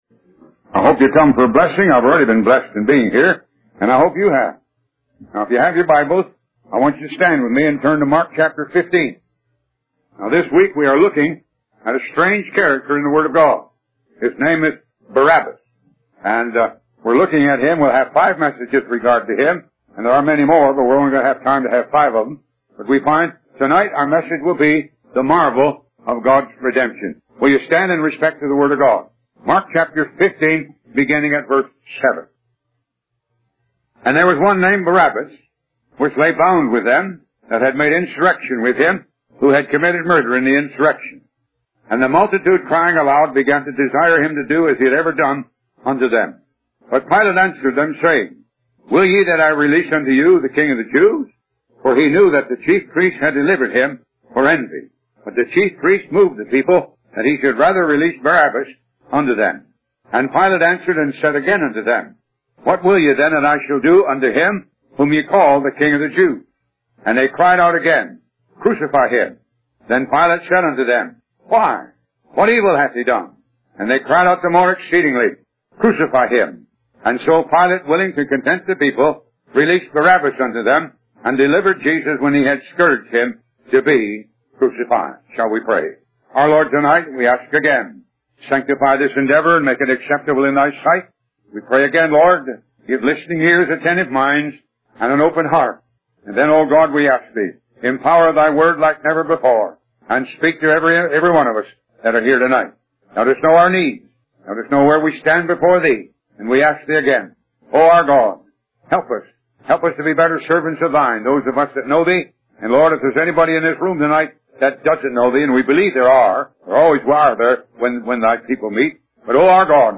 Bible Study
Talk Show